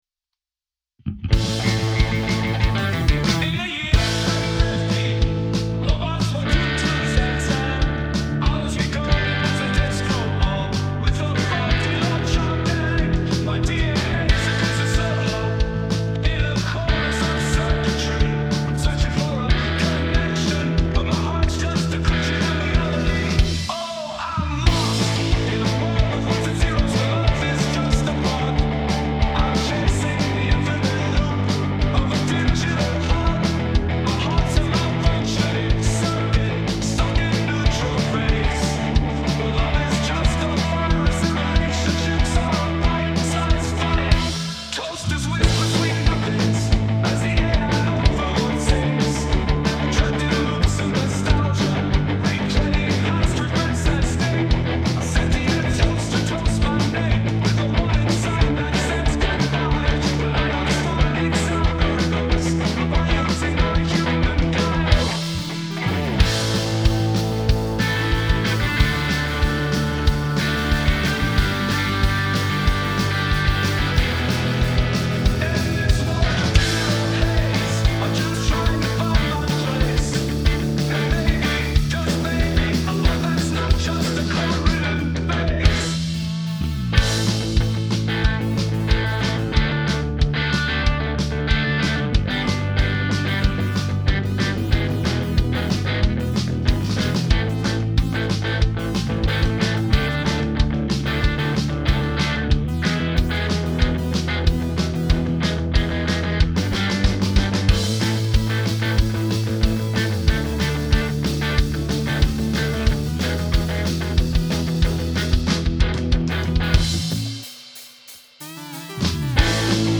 Drums https